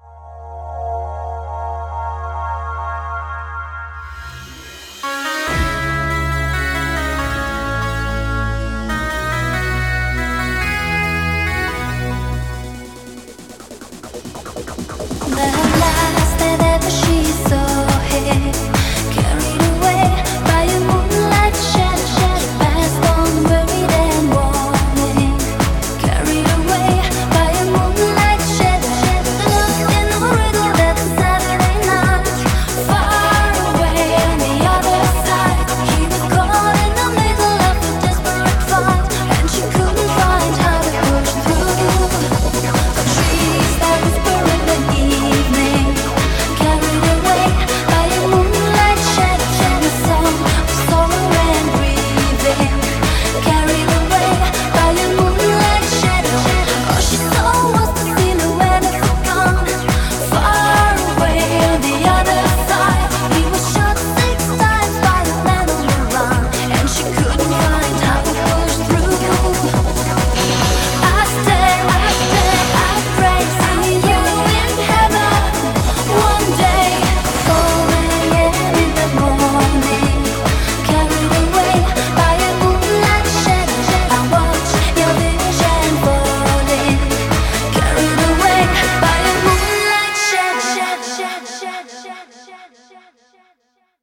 BPM140-140
Audio QualityCut From Video